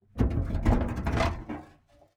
Metal_01.wav